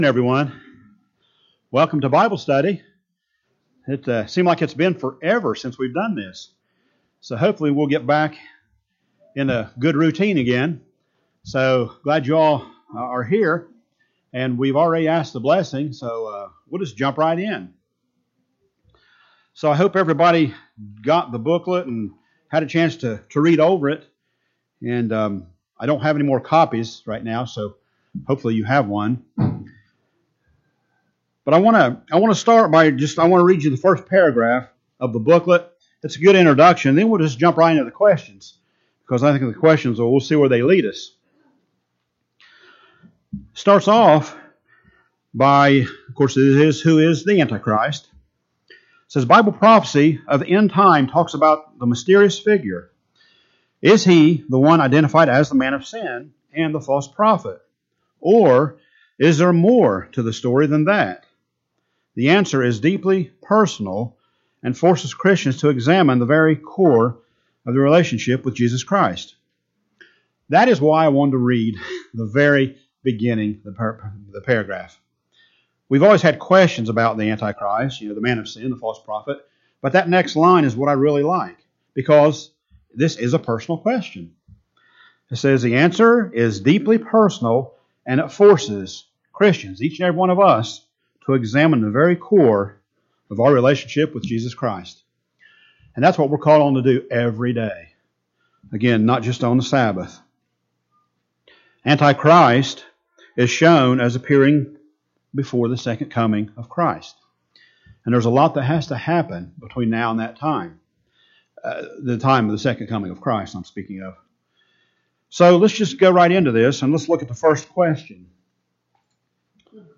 In this bible study we will cover the booklet "Who Is The Anti-Christ?" in detail.